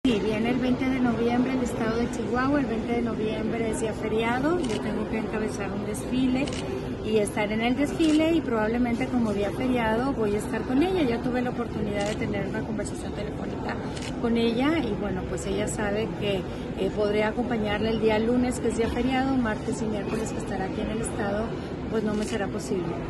AUDIO: MARÍA EUGENIA CAMPOS , GOBERNADORA DEL ESTADO DE CHIHUAHUA